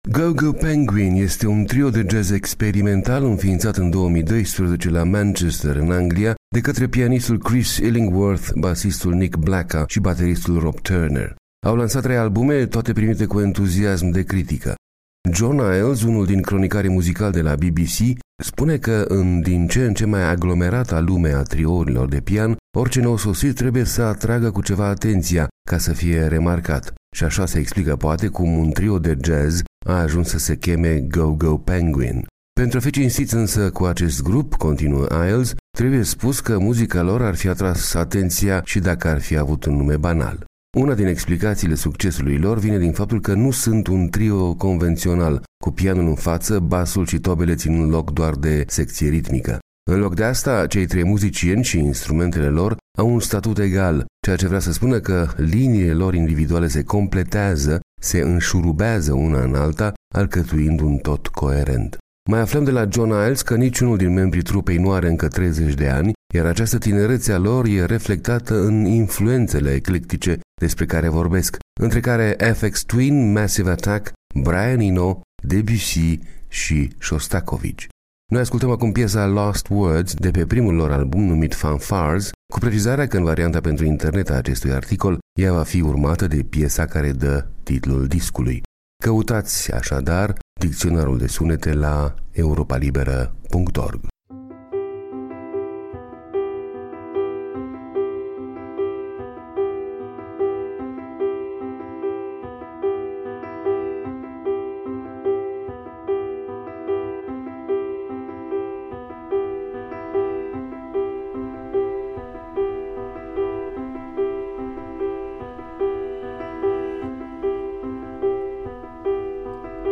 trio de jazz experimental